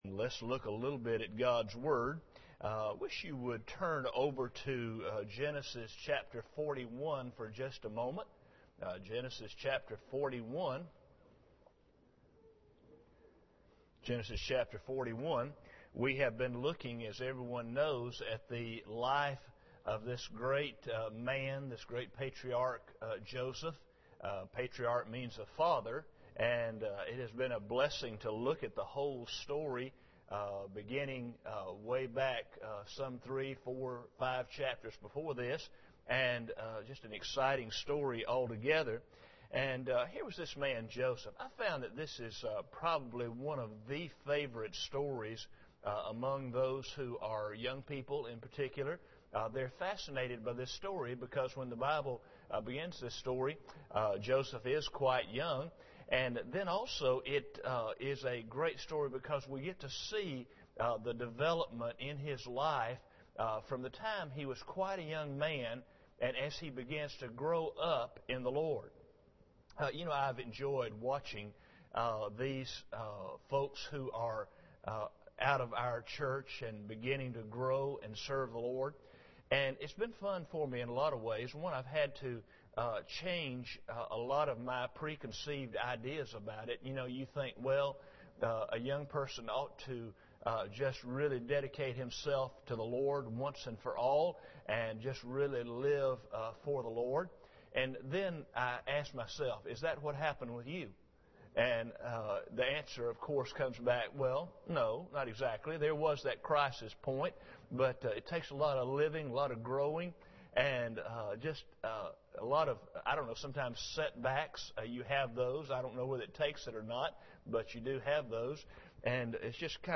Genesis 41:46 Service Type: Sunday Evening Bible Text